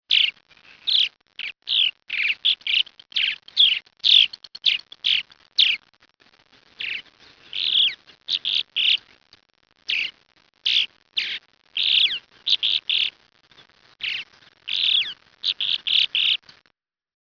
Progne chalybea (grey-breasted martin)
This is an example of their "song" - note the cadence.